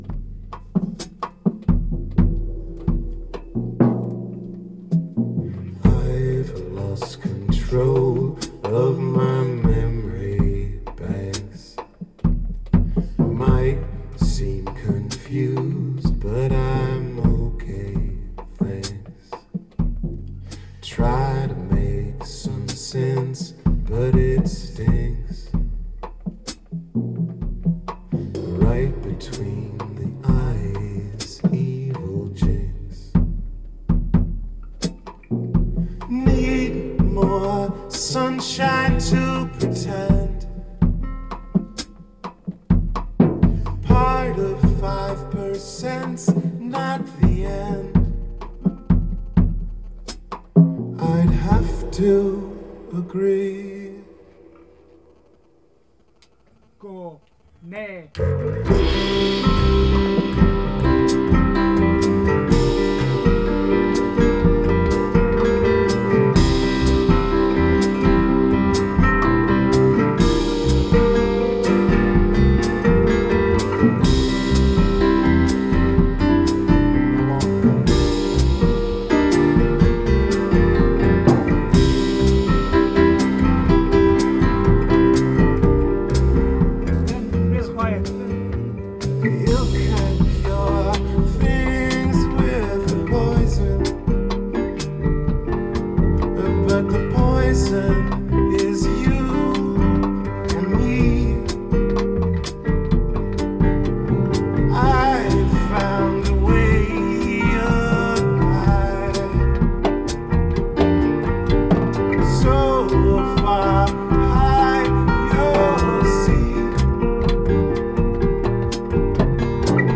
Rehearsals 16.8.2013